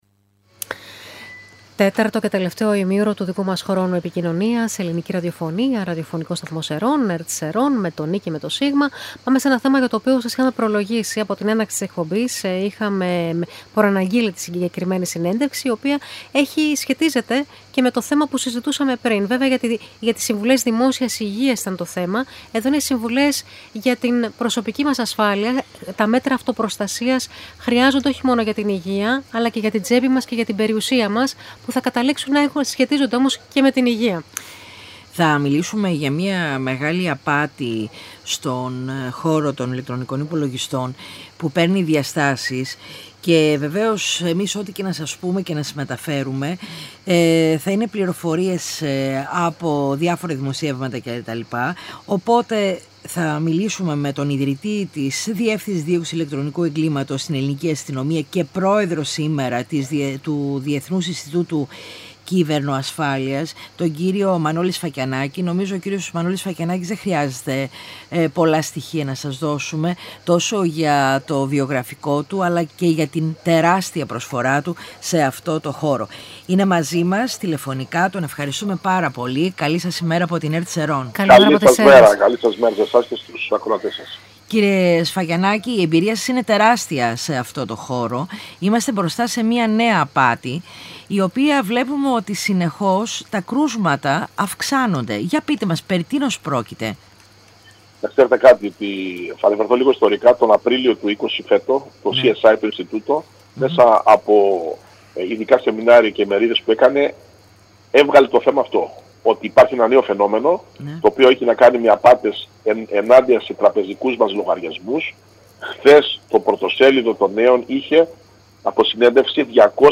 Συνέντευξη Μ. Σφακιανάκη στην ΕΡΤ Σερρών για τις απάτες στο διαδίκτυο